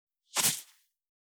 384ふりかけ,サラサラ,パラパラ,ジャラジャラ,サッサッ,ザッザッ,シャッシャッ,
効果音